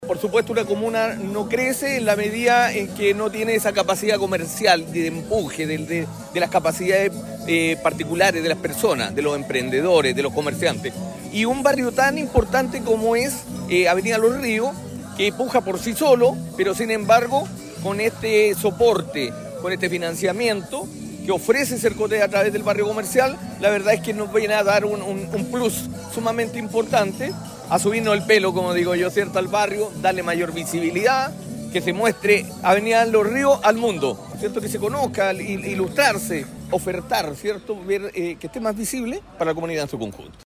Por su parte, el concejal y presidente de la Comisión de Fomento y Turismo de Laja, Freddy Castro, valoró la iniciativa: “Agradecemos esta oportunidad que entrega Sercotec, porque potencia el fomento y el comercio local”.